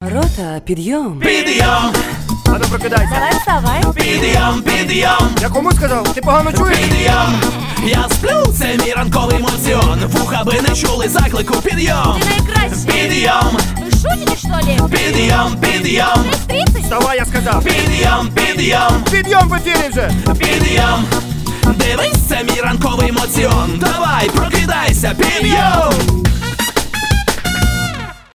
позитивные
рэп
веселые